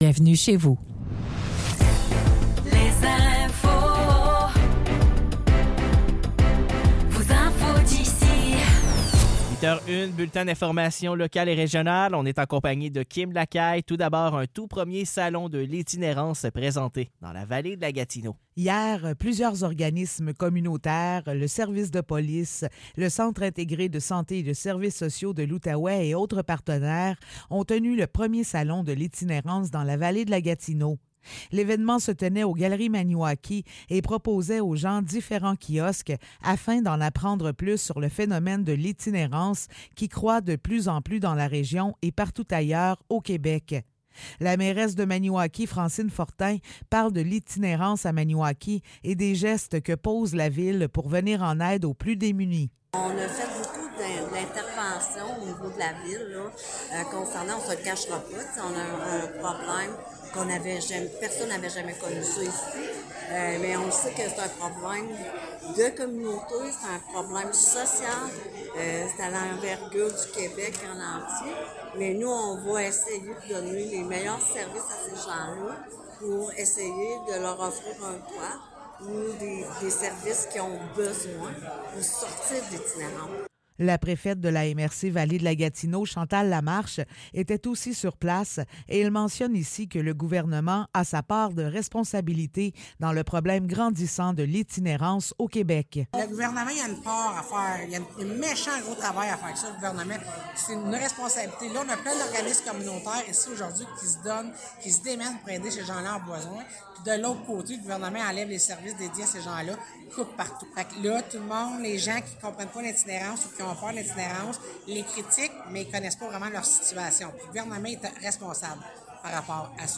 Nouvelles locales - 28 juin 2024 - 8 h